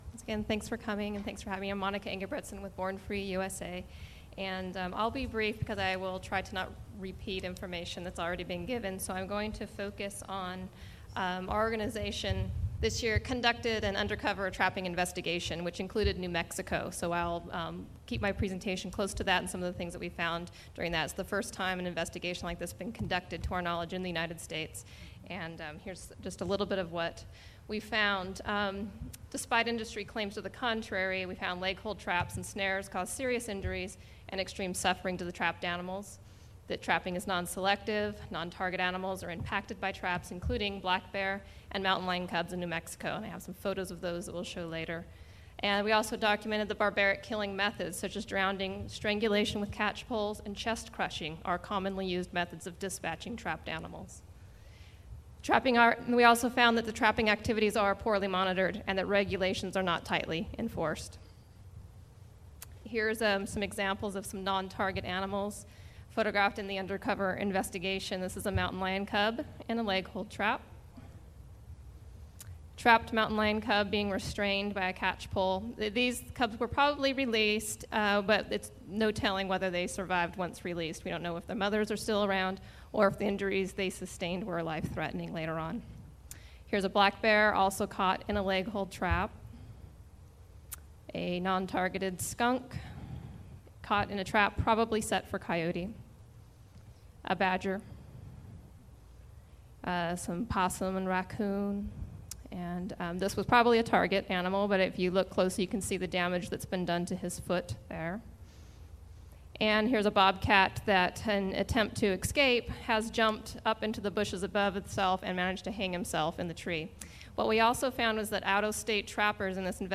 An audio recording was made of the proceedings of this public hearing.